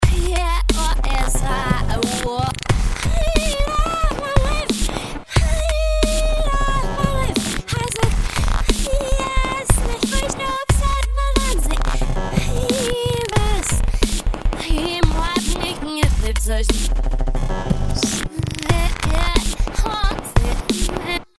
Hier bietet sich zunächst die Reverse-Funktion an. Rückwärts hört sich die selbe Gesangspassage gleich ganz anders an.